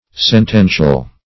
sentential - definition of sentential - synonyms, pronunciation, spelling from Free Dictionary
sentential \sen*ten"tial\, a.